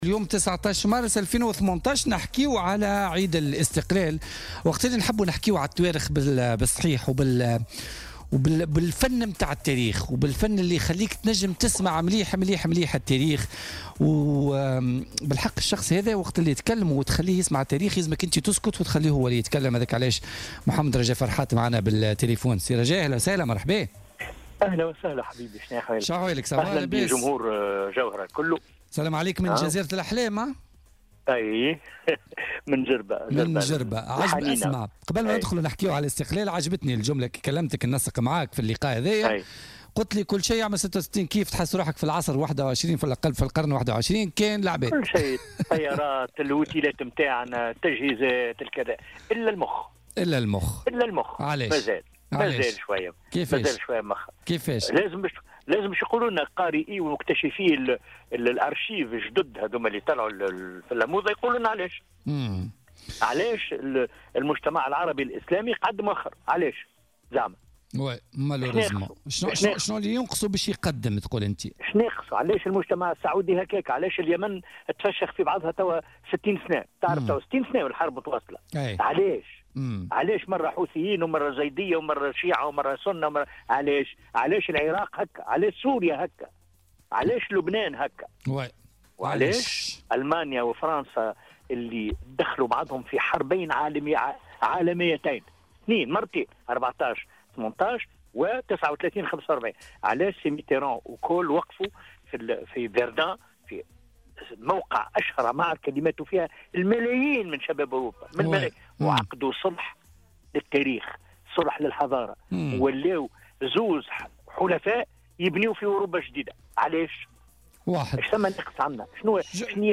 وتابع خلال مداخلة هاتفية له اليوم الاثنين 19 مارس 2018 في برنامج "بوليتيكا"، أن العقلية الضديّة أي ضد الدولة متأصلة في خلايا الشعب التونسي، نظرا لفترة الظلم الذي عاشها زمن الاستعمار، وتواصلت الكراهية ضد الزعيم الراحل الحبيب بورقيبة إلى فترة ما بعد الاستقلال".